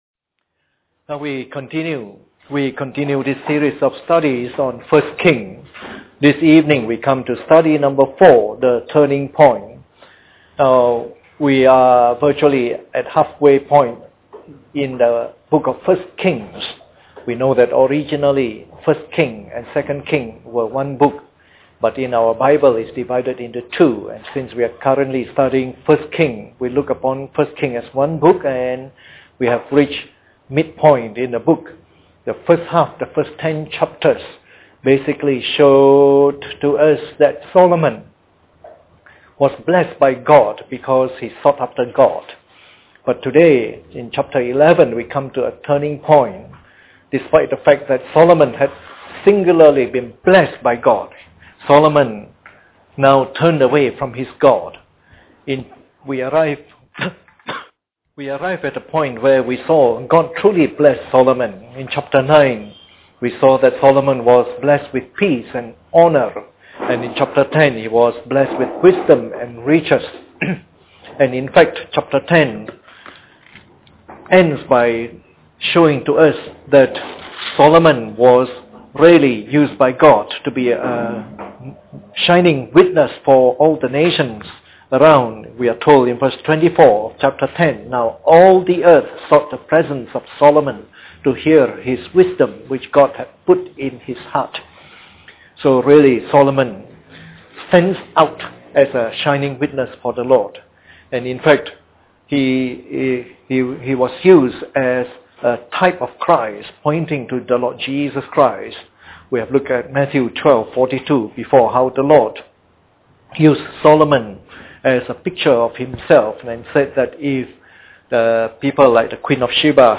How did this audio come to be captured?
Part of the “1 Kings” message series delivered during the Bible Study sessions.